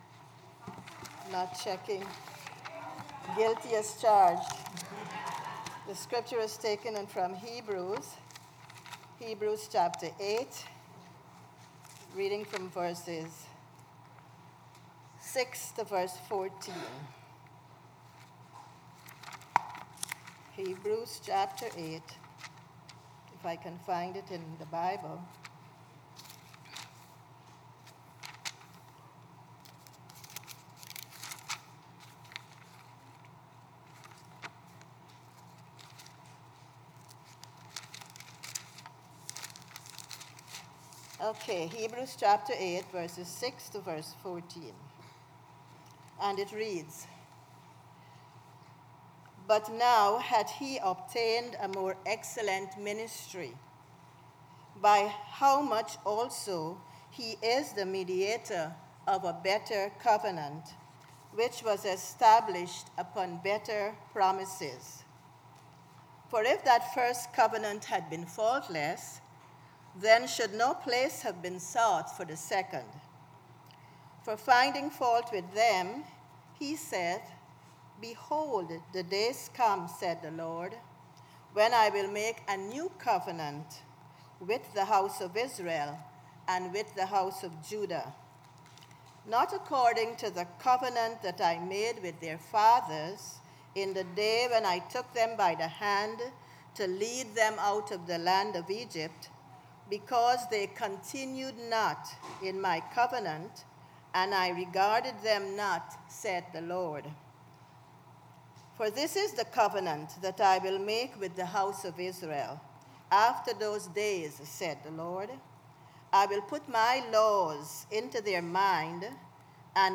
Worship Service 9/3/17